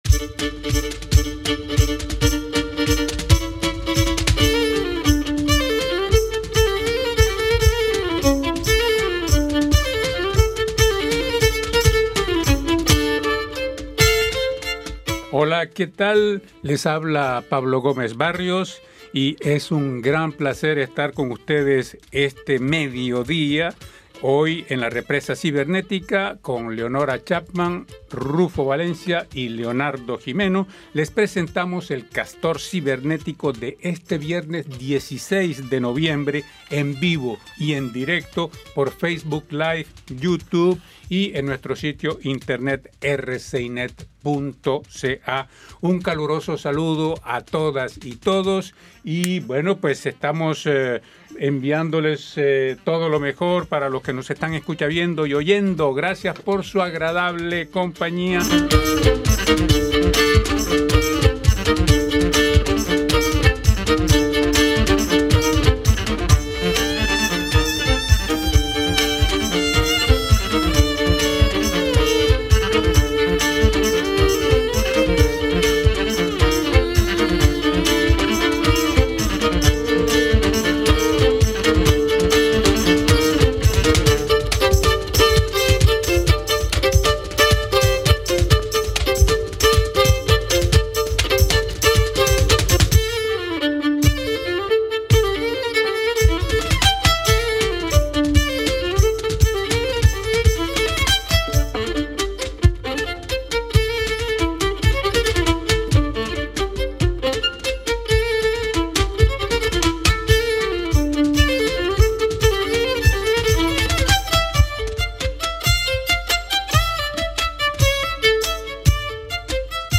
LA MÚSICA